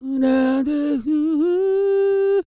E-CROON 3020.wav